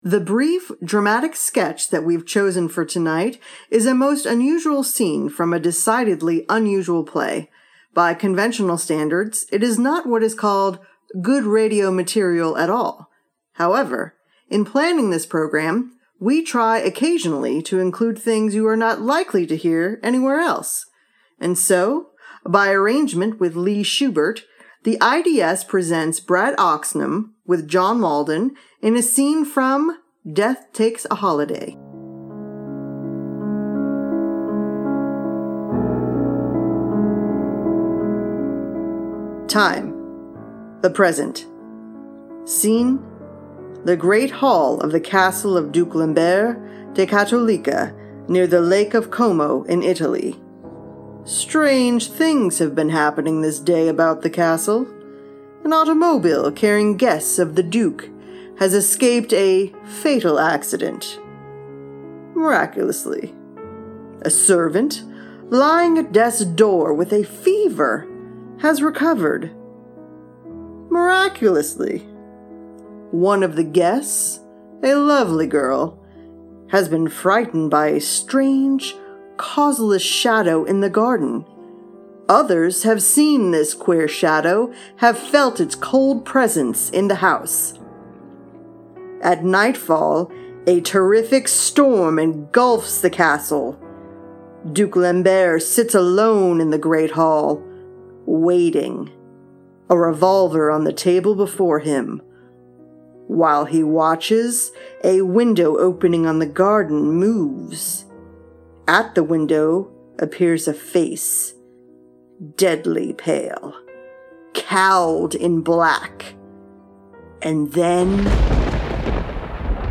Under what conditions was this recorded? For those that don’t know; during the summer, the IDS recreates old radio programs to the best of our abilities for your entertainment and our amusement.